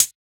Index of /musicradar/ultimate-hihat-samples/Hits/ElectroHat B
UHH_ElectroHatB_Hit-16.wav